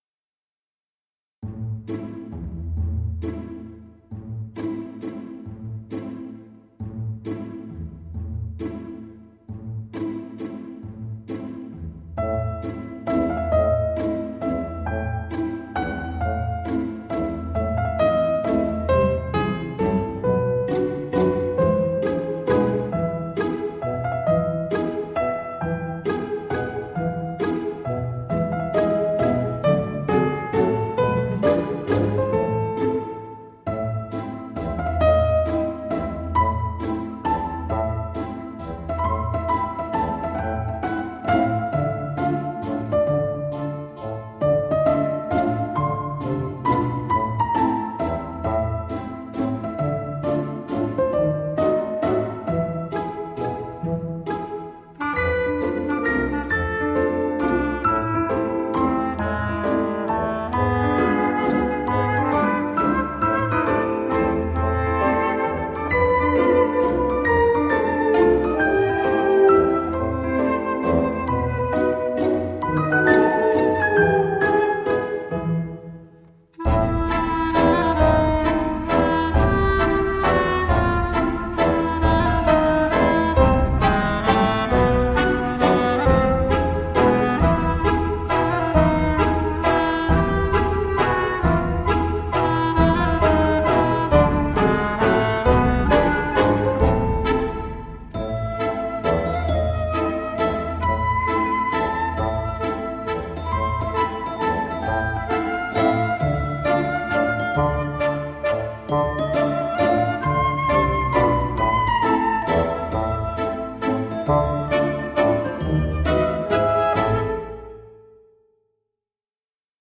Irish_Waltz.mp3